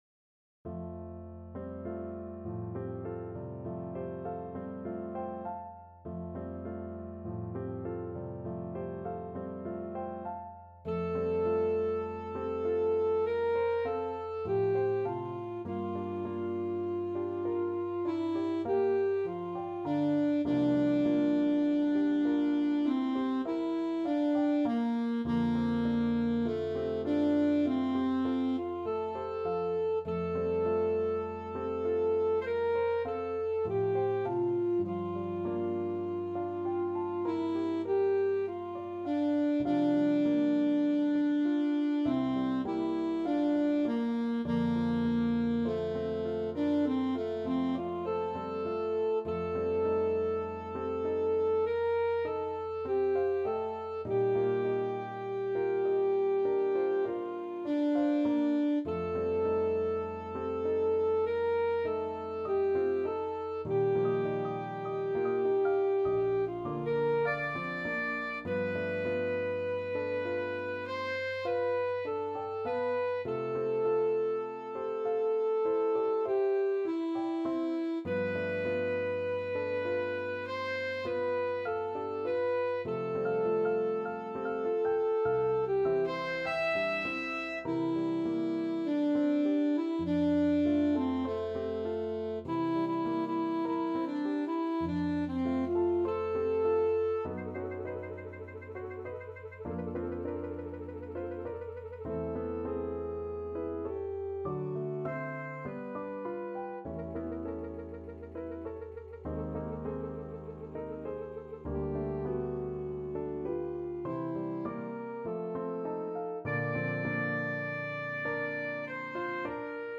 Alto Saxophone
Lento =50
Classical (View more Classical Saxophone Music)